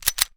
gun_pistol_cock_06.wav